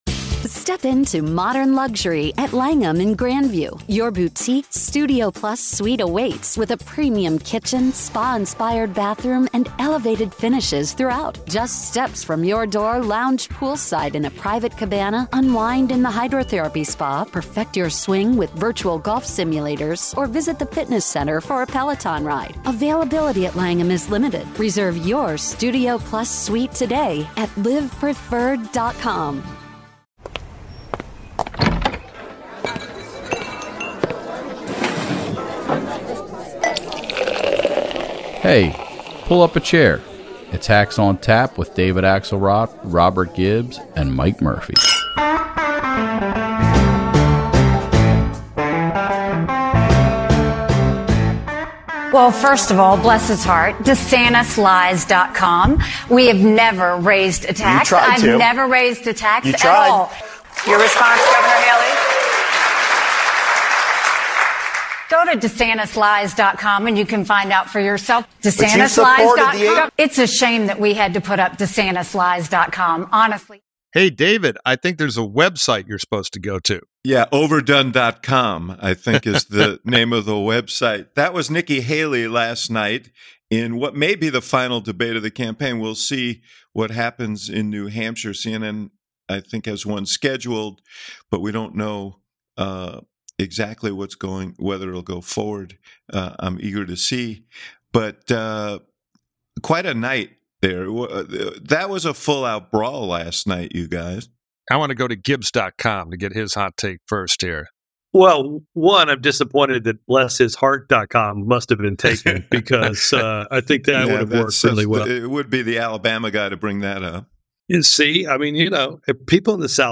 This week our trio of seasoned hacks dives headfirst into the CNN debate, mourns Chris Christie's campaign exit, and anticipates the Iowa Caucus outcomes.